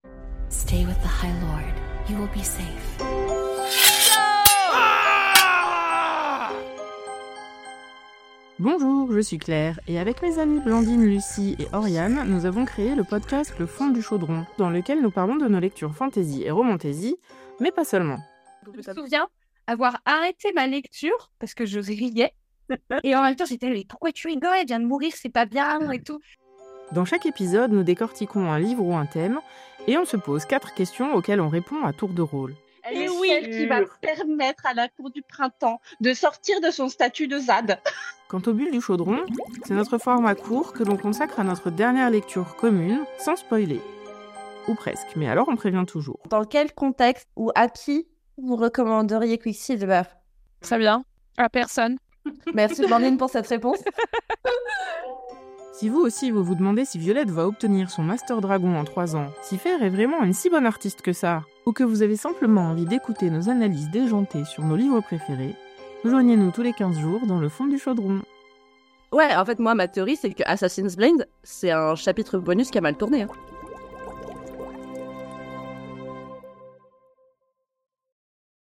Écouter le trailer